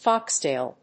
音節fóx・tàil 発音記号・読み方
/ˈfɑˌkstel(米国英語), ˈfɑ:ˌksteɪl(英国英語)/